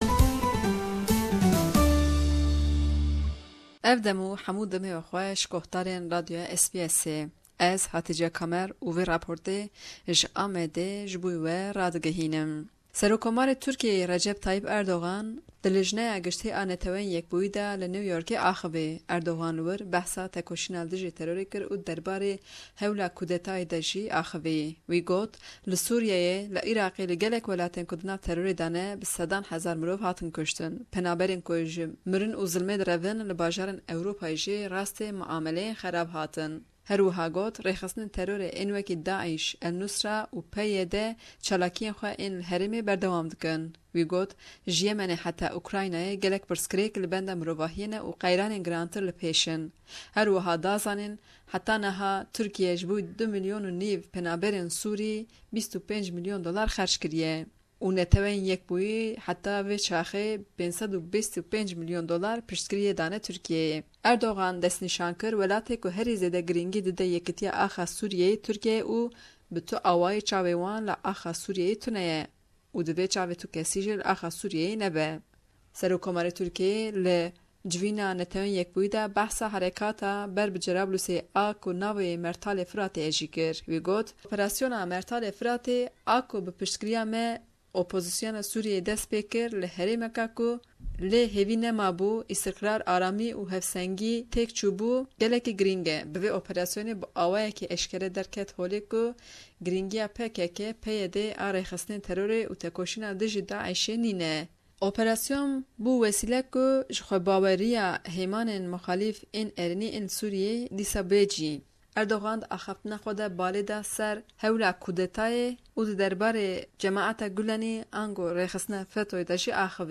Raporta ji Diyarbekir